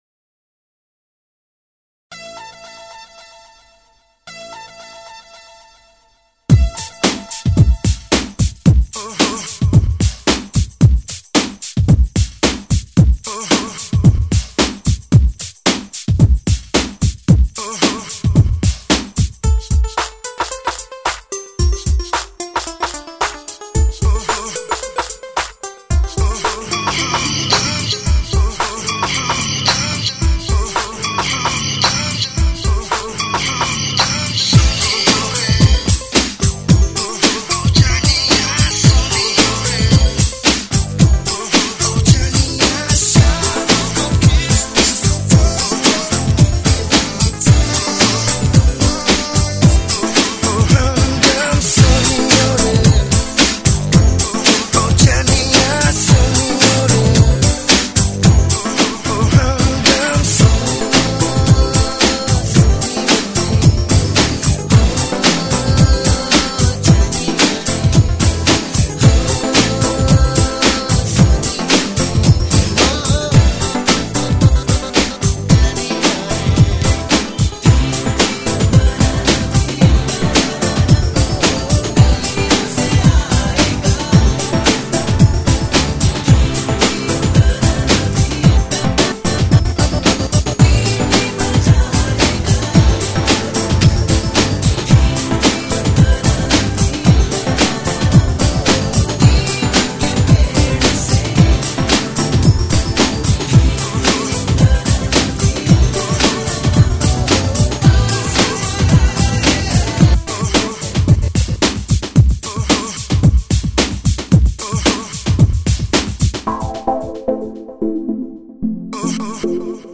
Breaks & beats